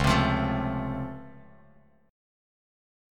C#+M7 chord